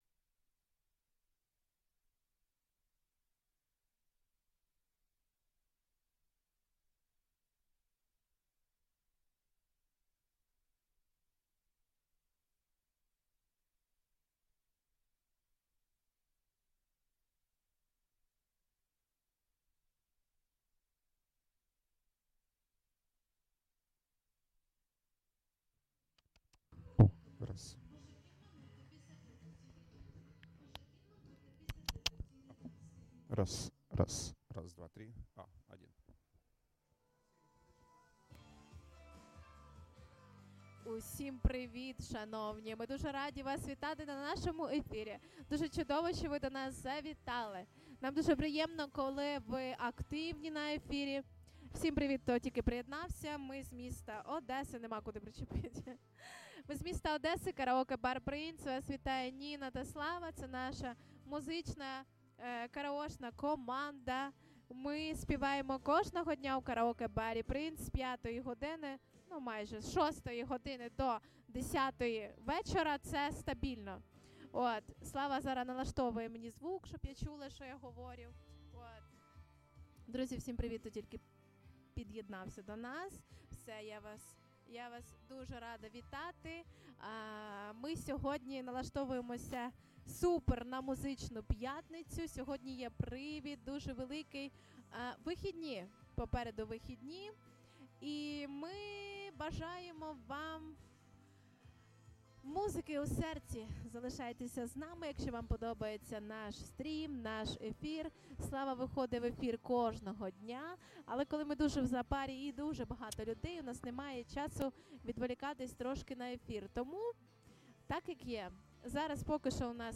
Постоянная ссылка URL (SEO) (SEO) Текущее время (SEO) Категория: Караоке вечори в Одесі Описание: Караоке Одеса караоке-бар "PRINCE"!